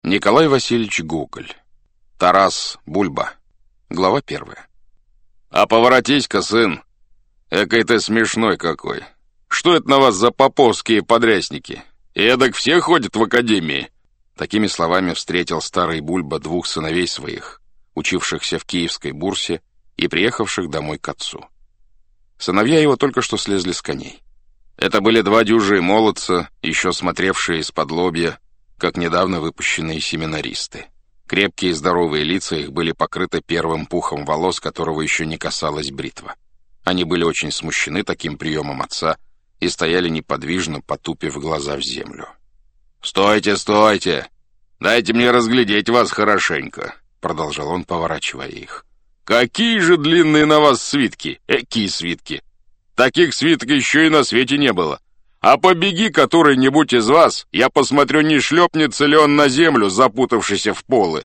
Аудиокнига Тарас Бульба | Библиотека аудиокниг
Aудиокнига Тарас Бульба Автор Николай Гоголь Читает аудиокнигу Александр Клюквин.